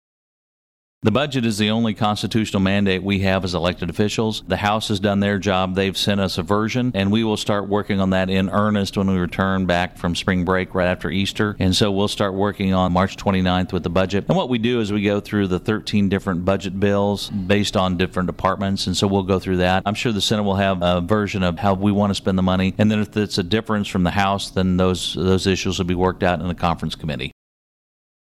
Senator Pearce adds he wants to add teacher retirement to Senate Bill 980, which would require public retirement plans to provide certain financial information to participants and modify the criteria for when a public retirement plan will be deemed delinquent.